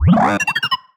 sci-fi_driod_robot_emote_15.wav